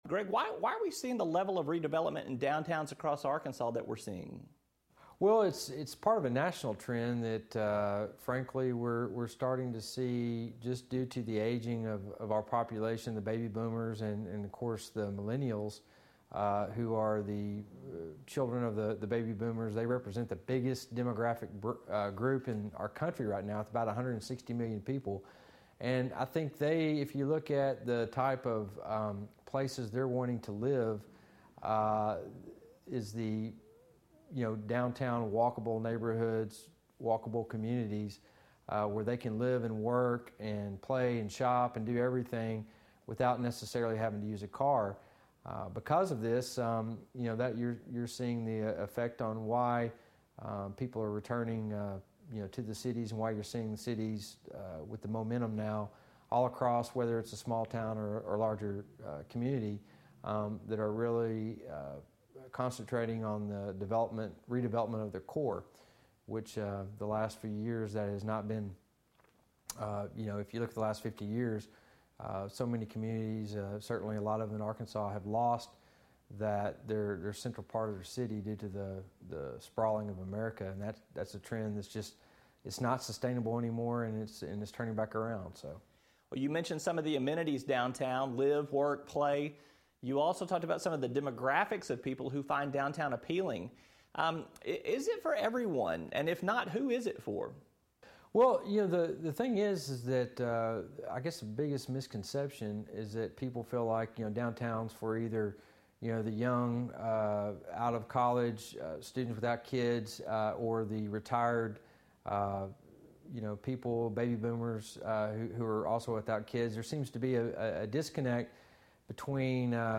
sits down with an advocate for bringing back downtowns in Arkansas cities.